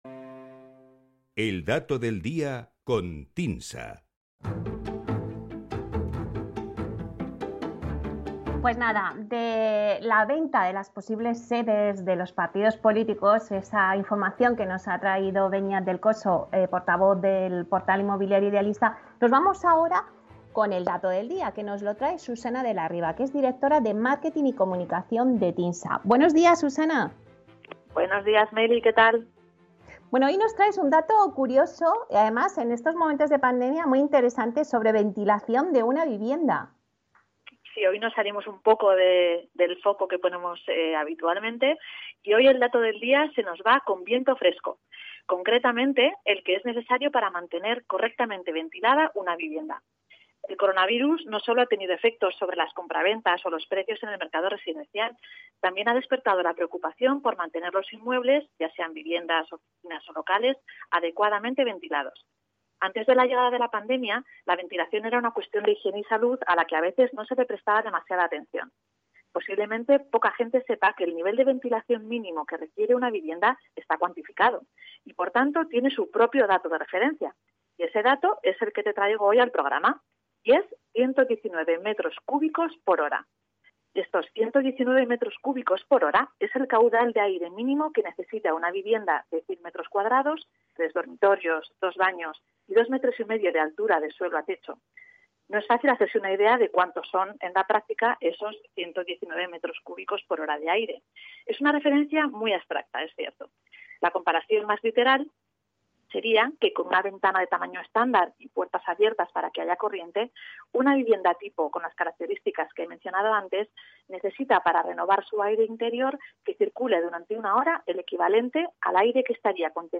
Escucha aquí la intervención completa de nuestra directora de Marketing y Comunicación en el programa Inversión Inmobiliaria de Capital Radio.